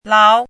怎么读
láo